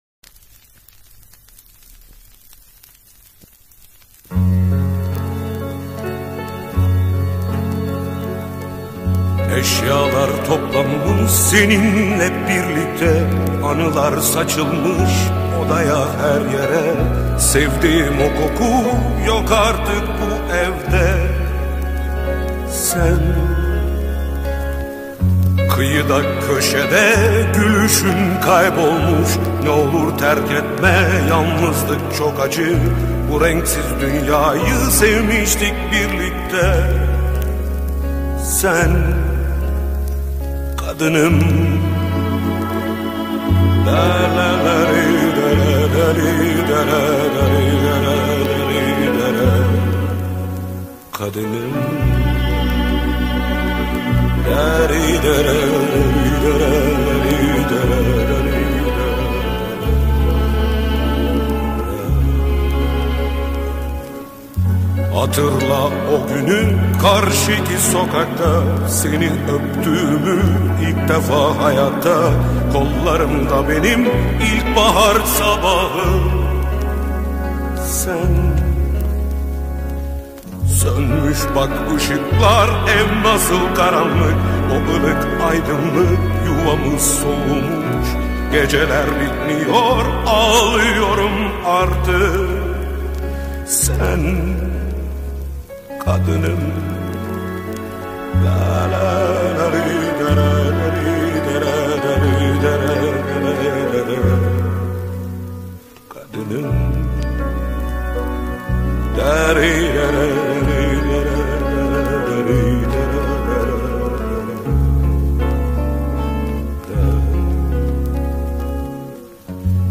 Turkish Pop, Pop